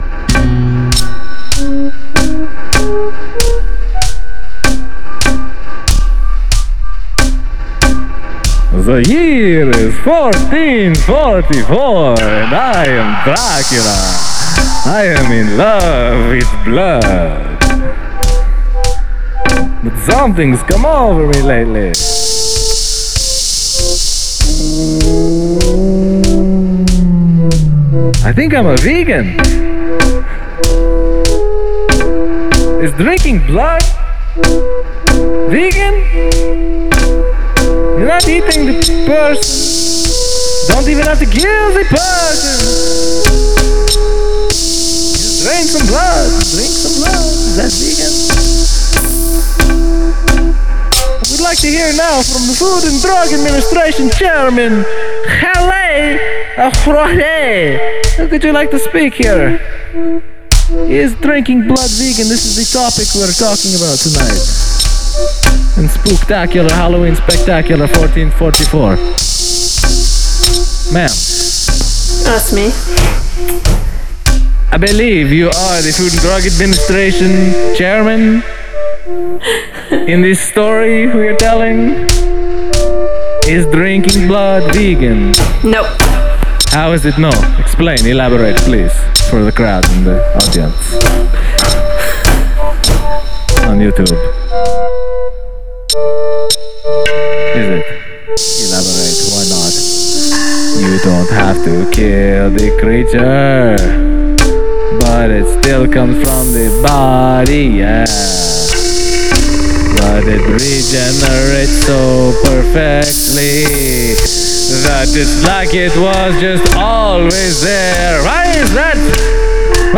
Spooky